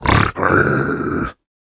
Minecraft Version Minecraft Version snapshot Latest Release | Latest Snapshot snapshot / assets / minecraft / sounds / mob / piglin / angry2.ogg Compare With Compare With Latest Release | Latest Snapshot
angry2.ogg